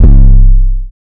808 [ Trophies ].wav